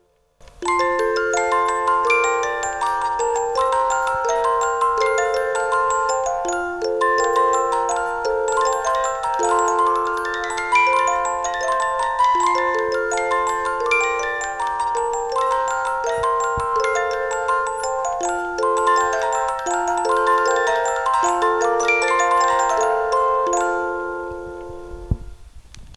Mouvement musical : 36 lames